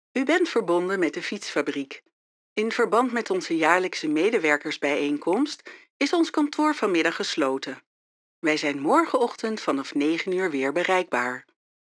Travieso, Versátil, Seguro, Amable, Cálida
Audioguía